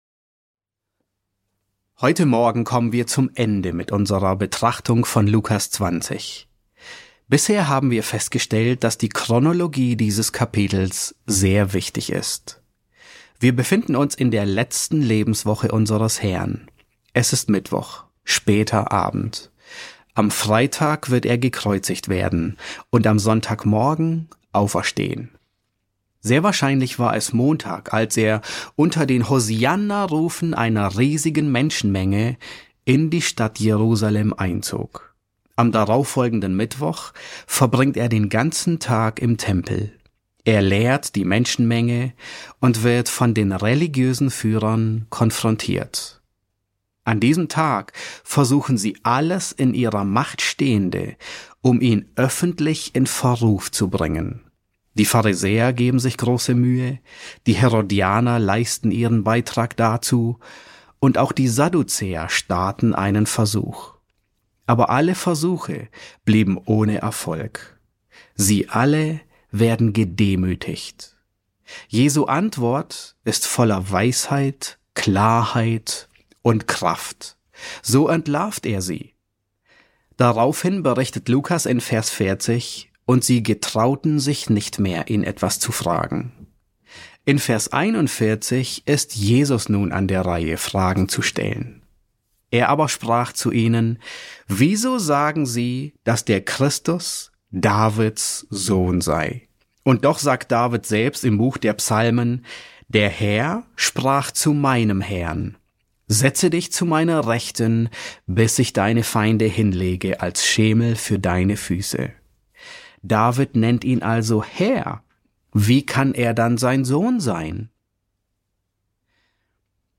S7 F7 | Davids Sohn und Herr ~ John MacArthur Predigten auf Deutsch Podcast